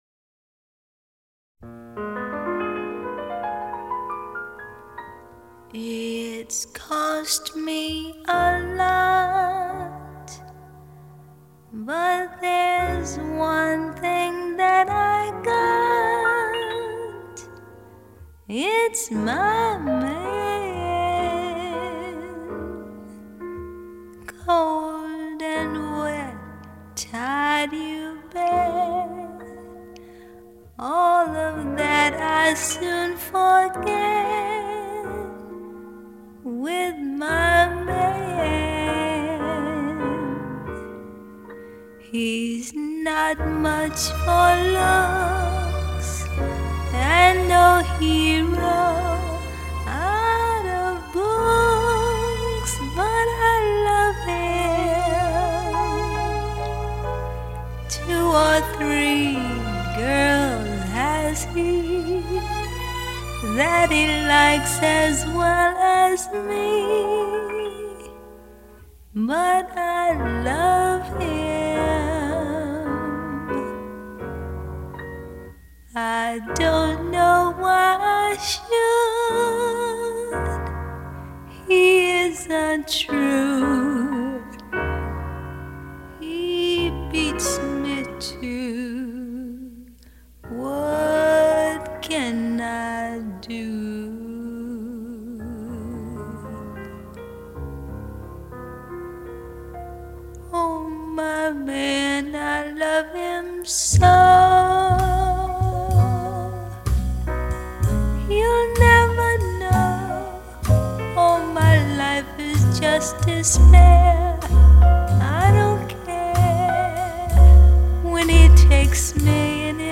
[light]獨特悠閒的音樂空間，36首最能掌握時代脈動的爵士樂精選 !